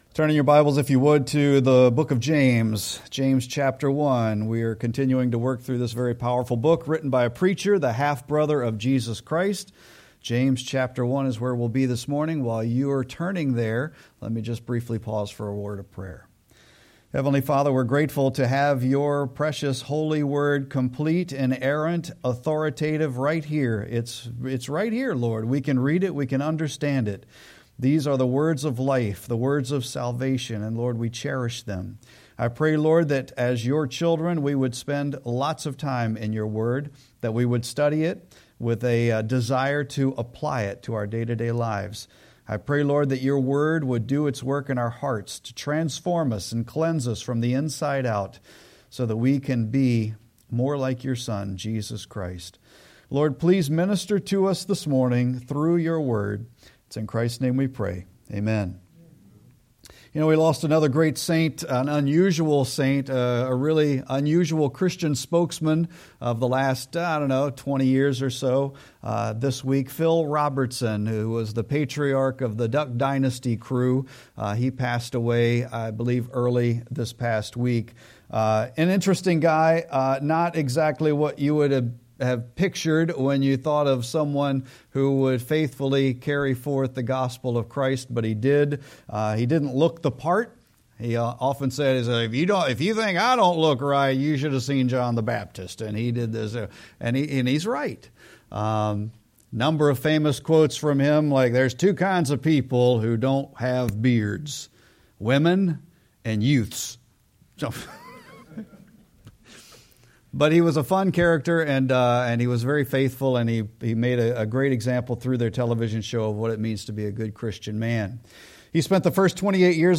Sermon-6-1-25.mp3